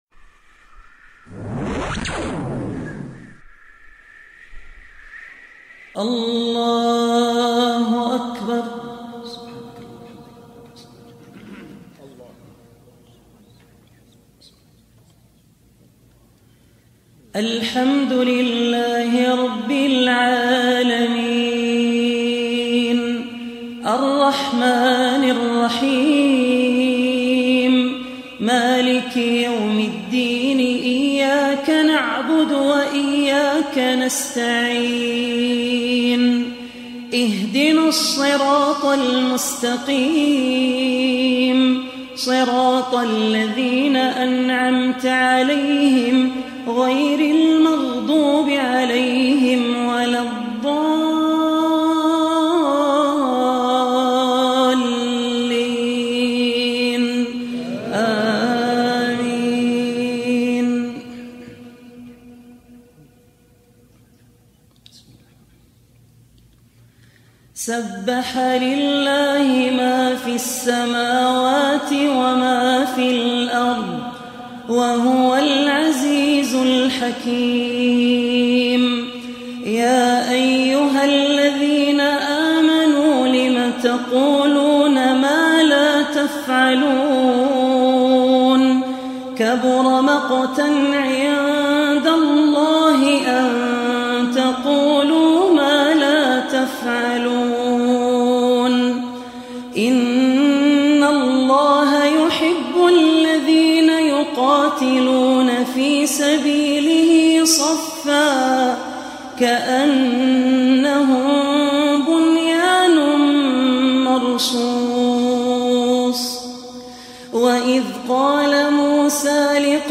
Surah As-Saff Online Recitation by Al Ausi
Surah As-Saff, listen online mp3 tilawat / recitation in arabic recited by Sheikh Abdur Rahman Al Ossi.